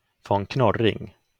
uttal(fil)) är en tysk adelsätt, som leder sitt ursprung från (von) Knorr av tysk uradel ursprungligen från Eichsfeld, bergsområdet Harz, Thüringen belagd från 1070.